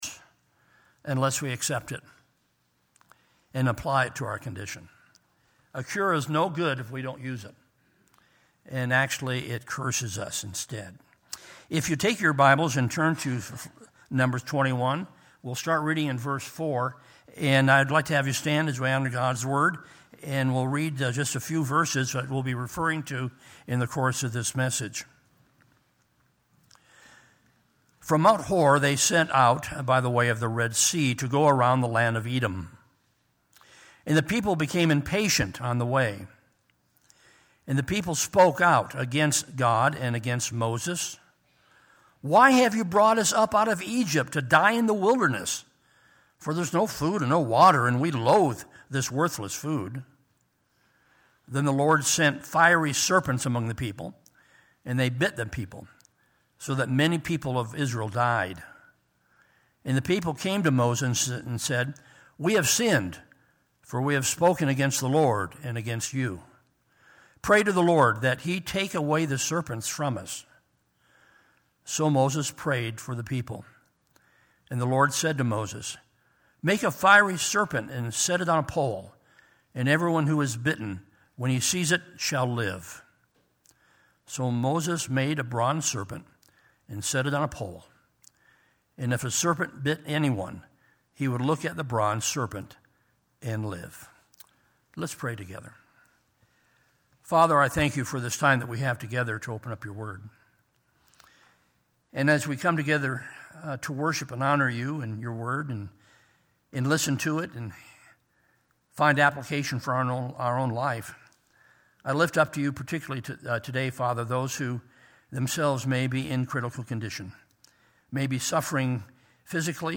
Sermons - Solid Rock Christian Fellowship
" General " Sermon Notes Facebook Tweet Link Share Link Send Email